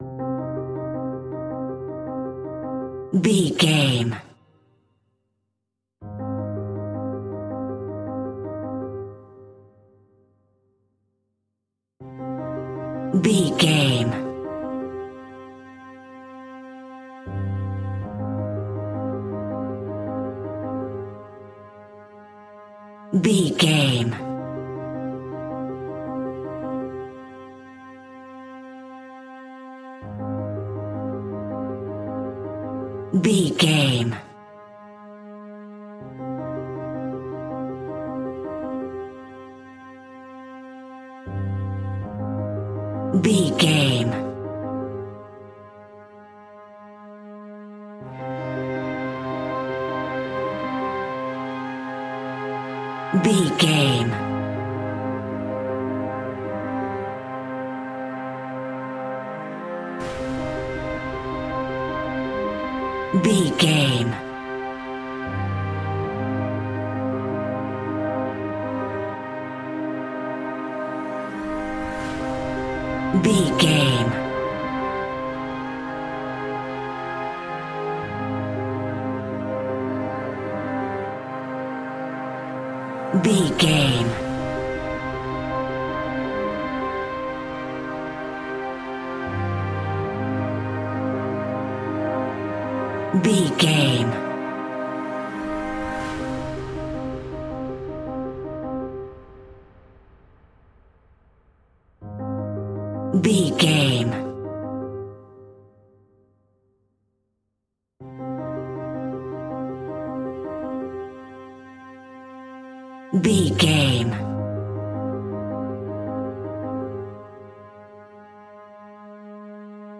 Aeolian/Minor
tension
ominous
haunting
eerie
horror piano